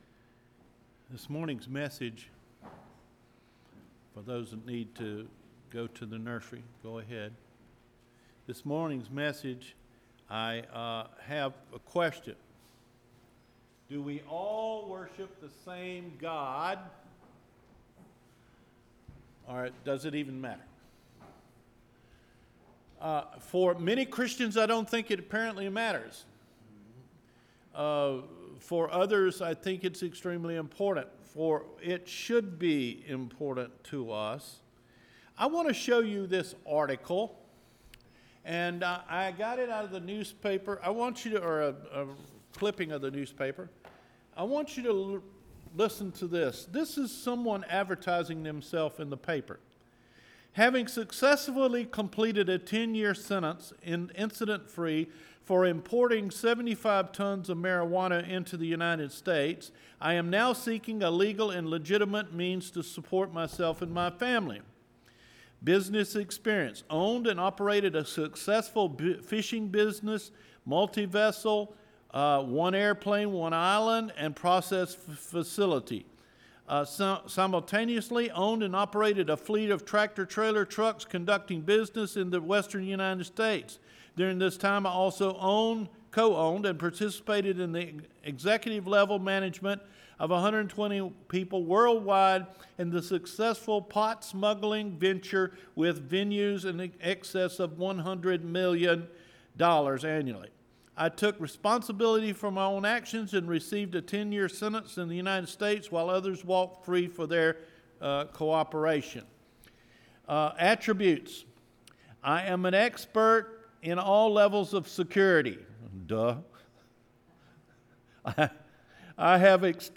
– JULY 21 SERMON – Cedar Fork Baptist Church